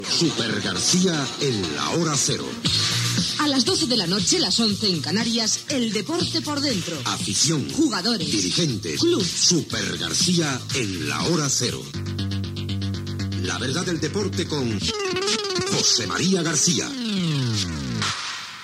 Promoció del programa
Esportiu